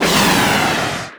Missile.wav